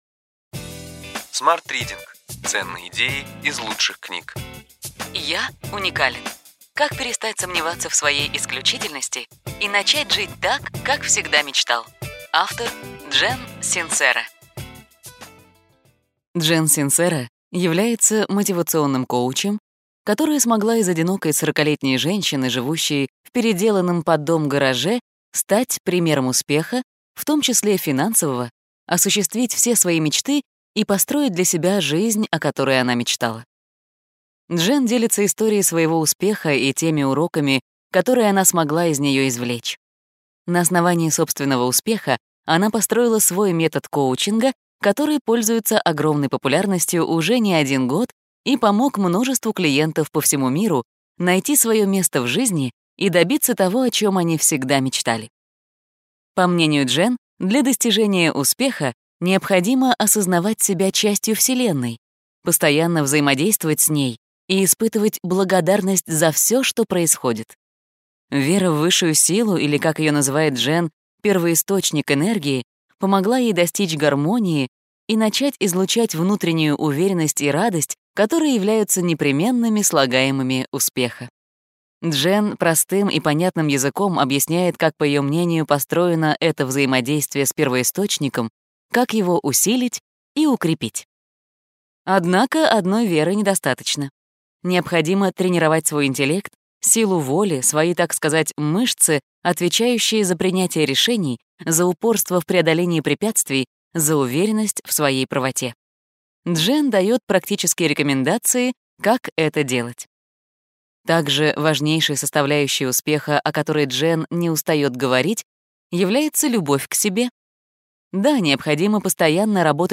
Аудиокнига Ключевые идеи книги: Я уникален: как перестать сомневаться в своей исключительности и начать жить так, как всегда мечтал.